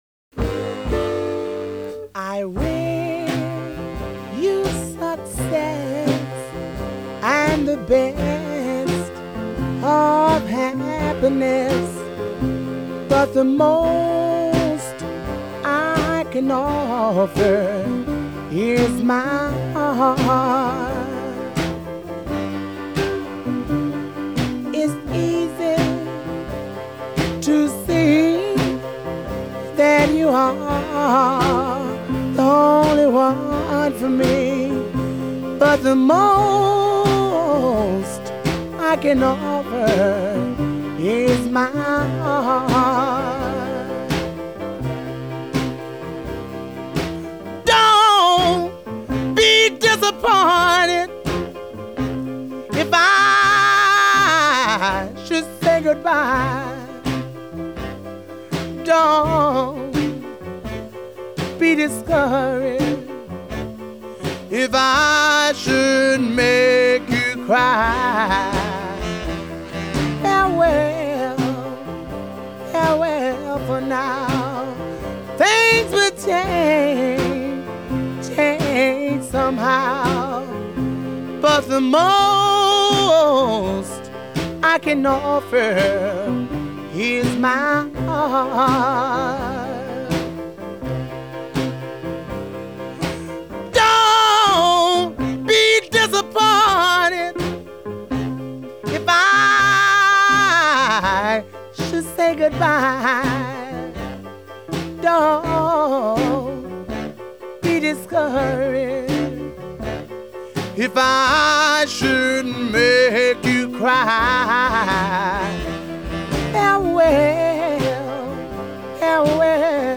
Category: studio outtakes
a mid-paced soul-shaking break-up ballad